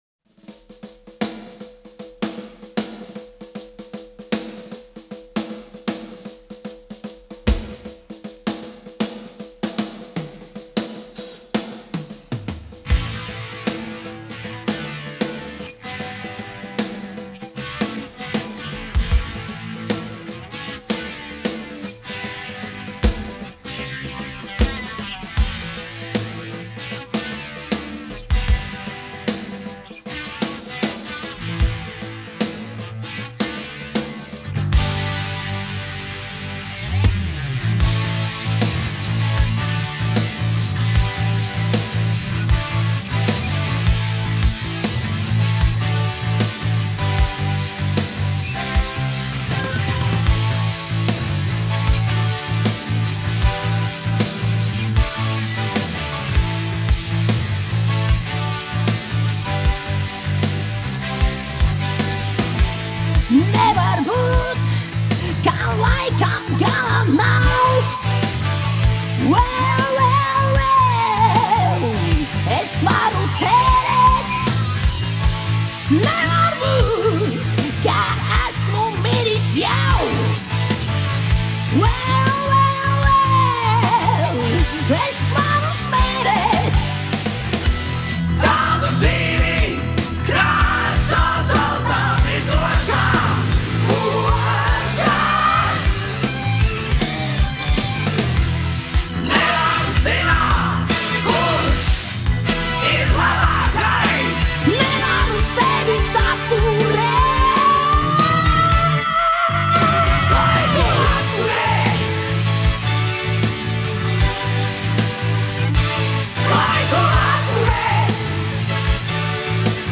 Populārā mūzika
Dziesmas